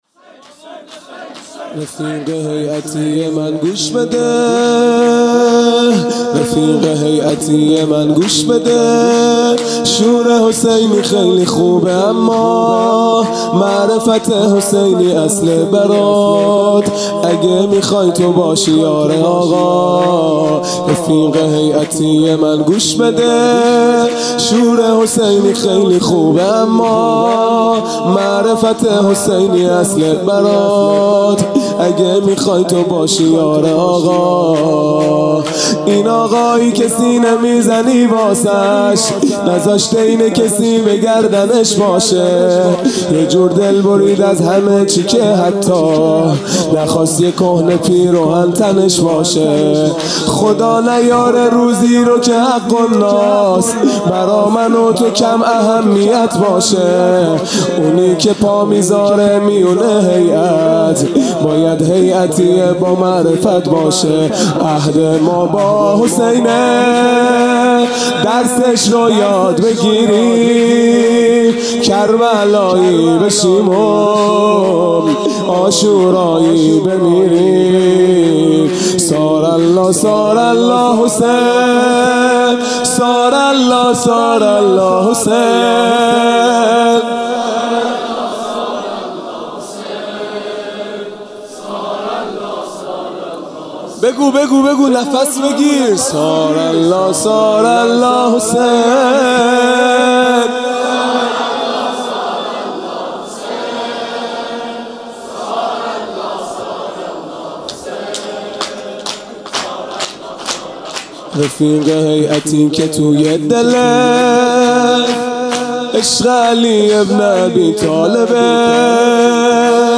خیمه گاه - هیئت بچه های فاطمه (س) - شور پایانی | رفیق هیئتی
محرم 1441 | شب دهم